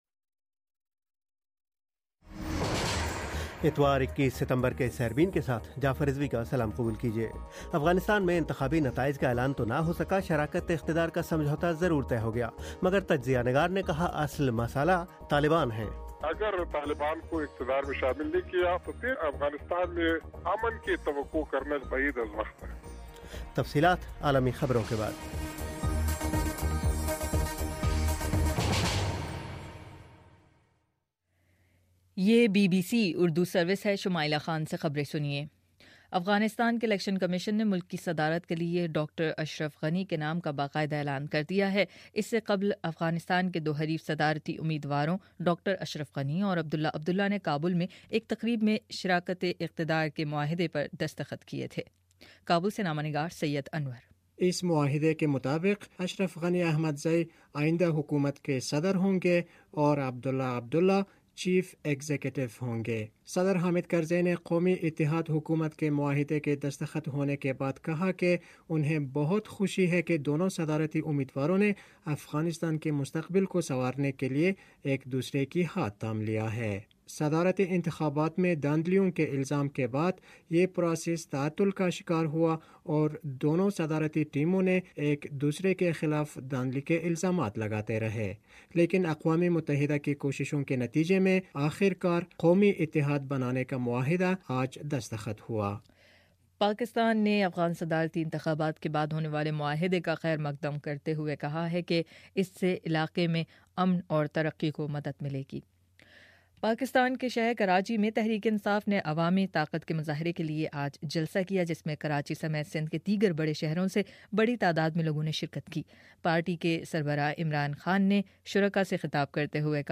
سنیچر 21 ستمبر کا سیربین ریڈیو پروگرام